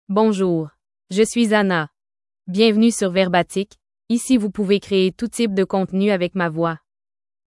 FemaleFrench (Canada)
AnnaFemale French AI voice
Anna is a female AI voice for French (Canada).
Voice sample
Anna delivers clear pronunciation with authentic Canada French intonation, making your content sound professionally produced.